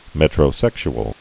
(mĕtrə-sĕksh-əl)